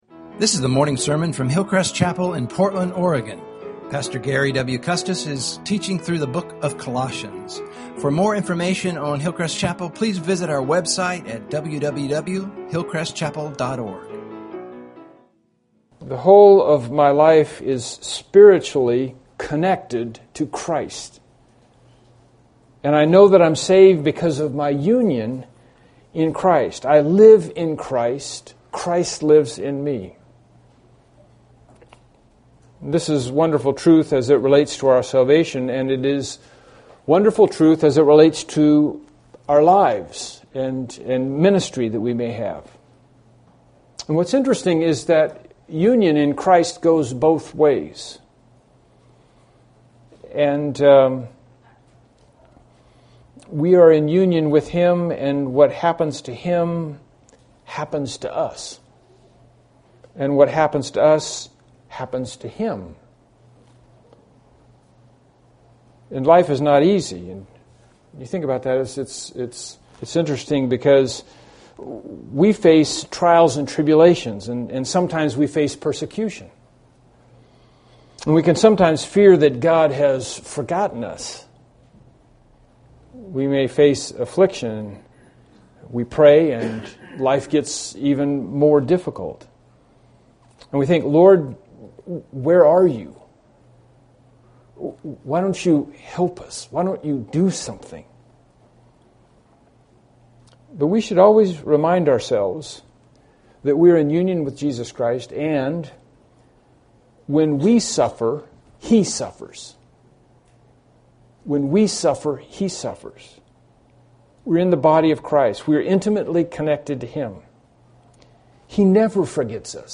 Bible Text: Colossians 1:24-25 | Preacher